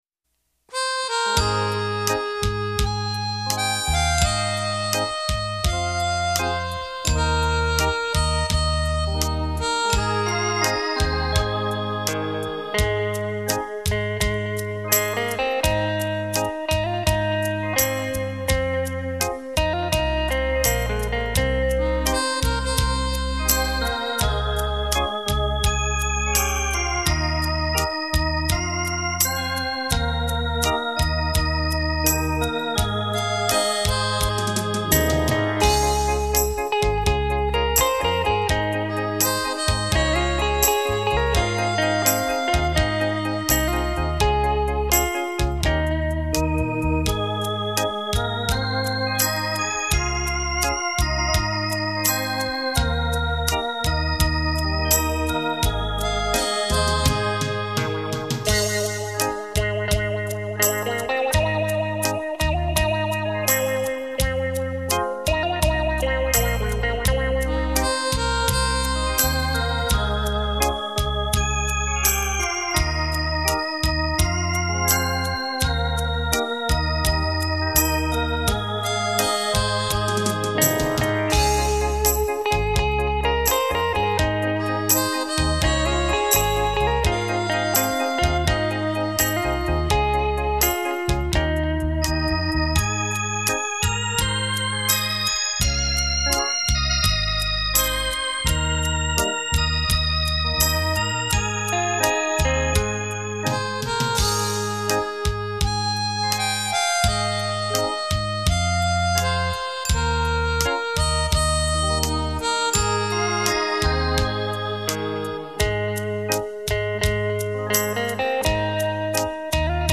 飄渺的音符精靈，邀心傾聽世上最美的聲音。